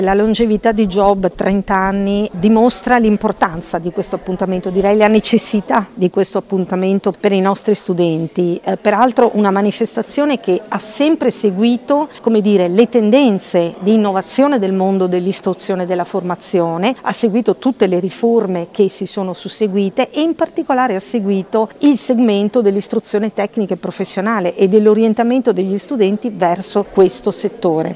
Carmela Palumbo, direttore generale dell’Ufficio scolastico regionale per il Veneto:
Interviste